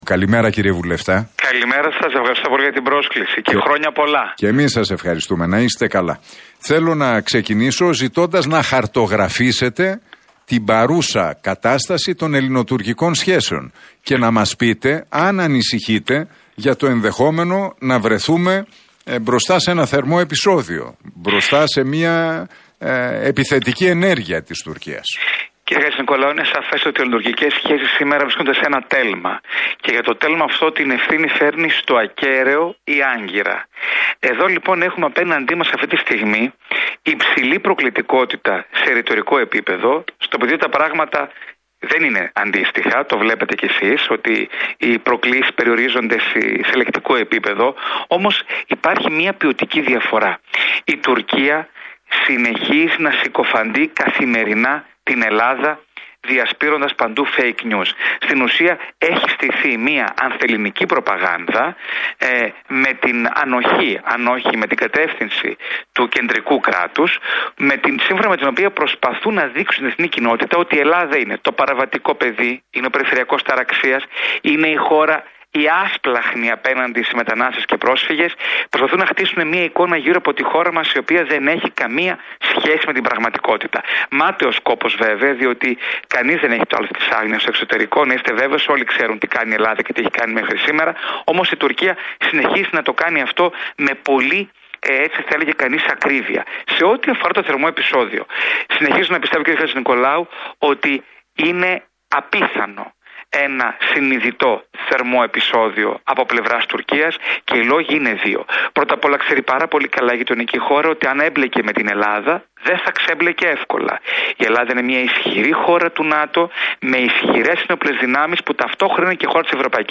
Για τις δηλώσεις του Χουσεΐν Ζεϊμπέκ αλλά και για τα ελληνοτουρκικά μίλησε στον Realfm 97,8 και την εκπομπή του Νίκου Χατζηνικολάου ο βουλευτής της ΝΔ και διεθνολόγος, Τάσος Χατζηβασιλείου.